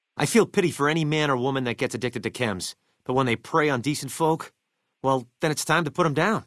Category:Fallout:_New_Vegas_audio_dialogues Du kannst diese Datei nicht überschreiben.